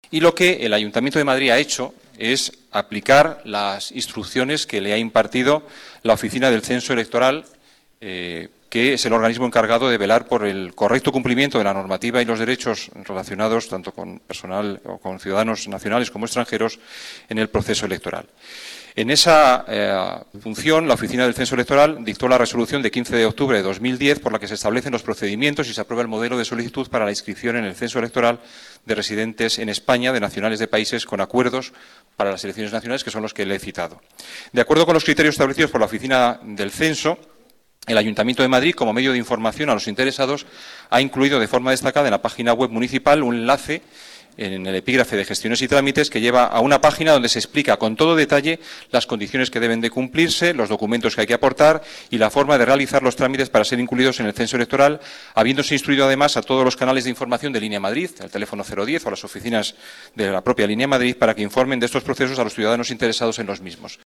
Nueva ventana:Declaraciones de Juan Bravo, teniente de alcalde y delegado de Hacienda